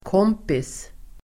Uttal: [k'åm:pis]